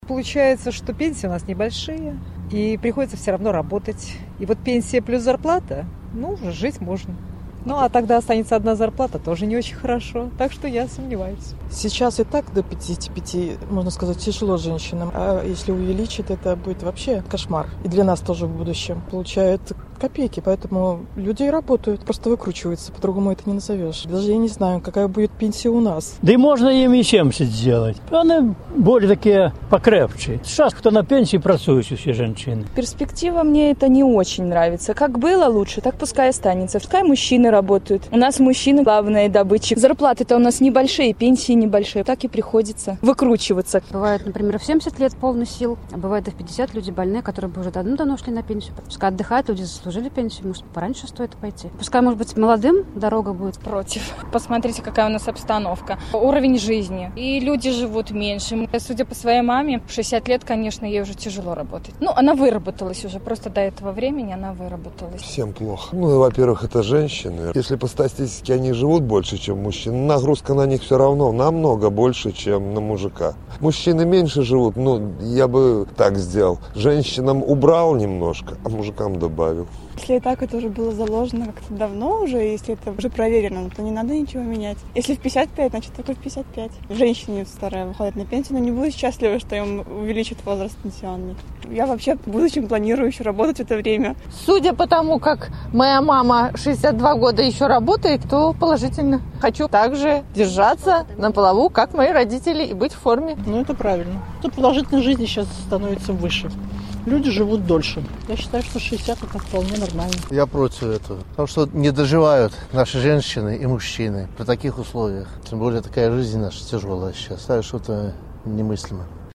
Адказвалі жыхары Магілёва.